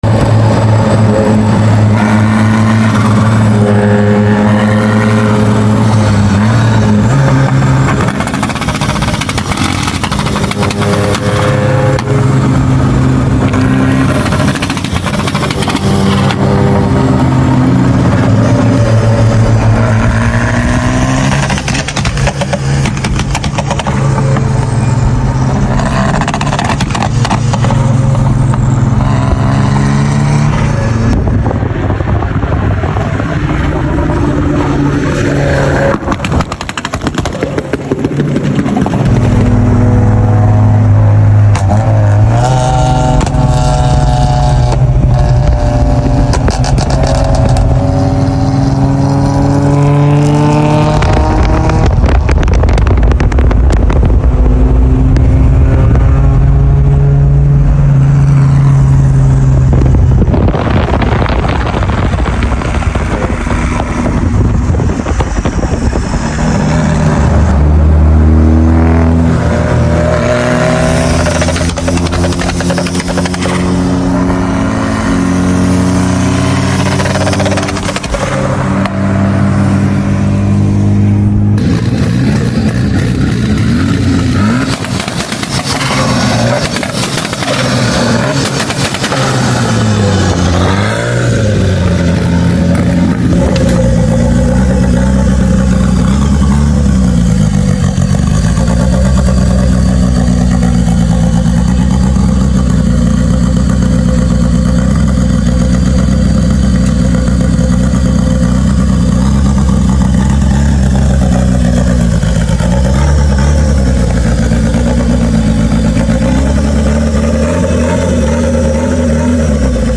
Raw sound civic sb3, alhamdulilah sound effects free download